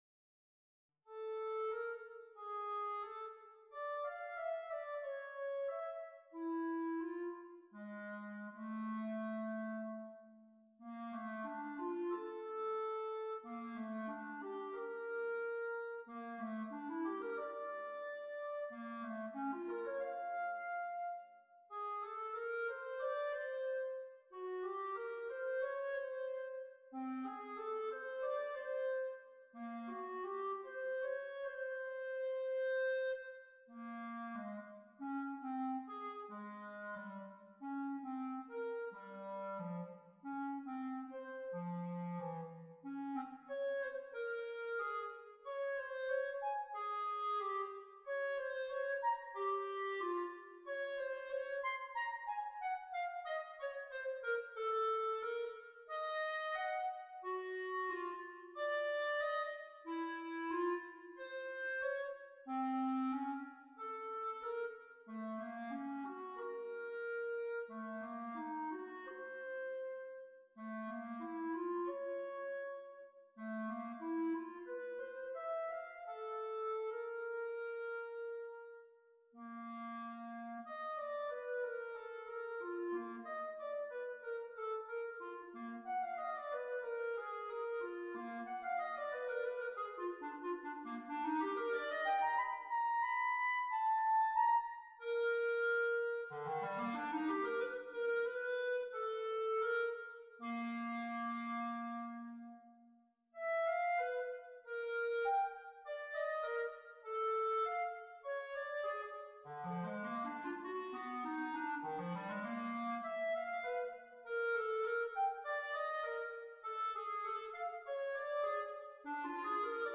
for solo clarinet